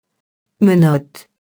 menotte [mənɔt] nom féminin (dimin. de main)